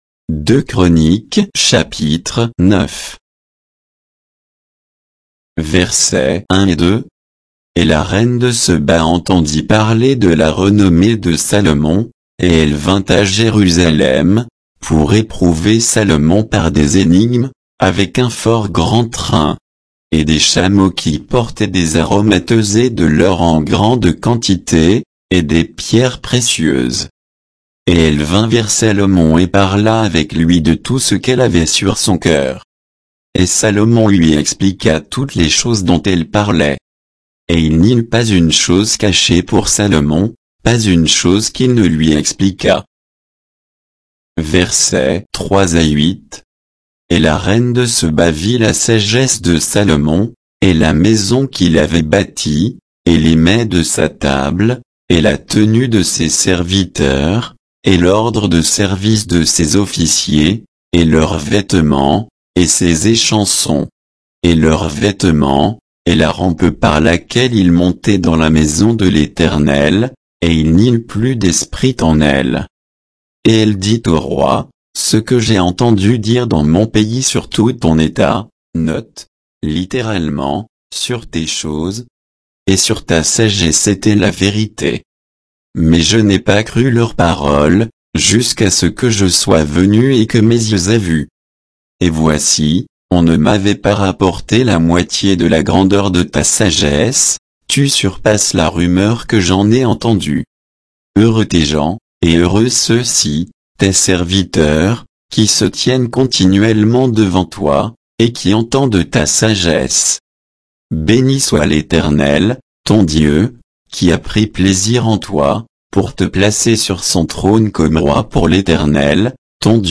Bible_2_Chroniques_9_(avec_notes_et_indications_de_versets).mp3